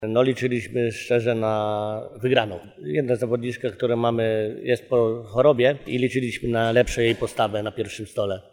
Trener-gospodarzy_01.mp3